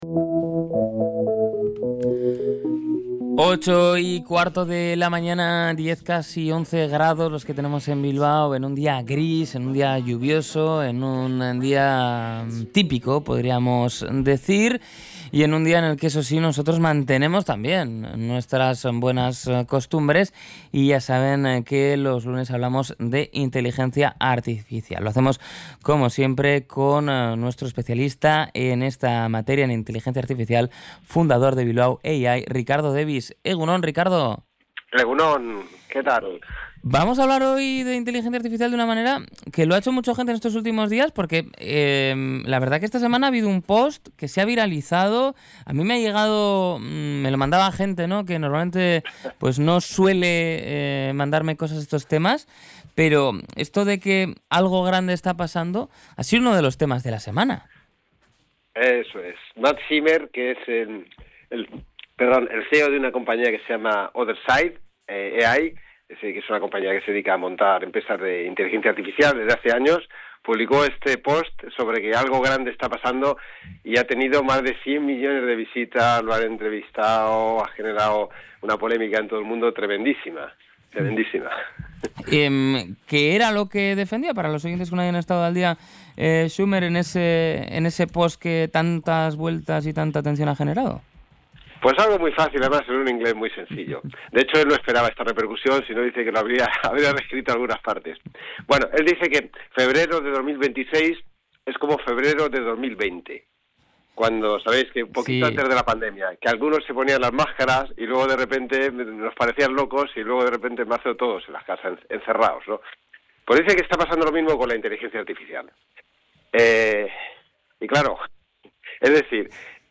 Uno de los puntos más impactantes de la entrevista es la descripción de las capacidades actuales de estos modelos.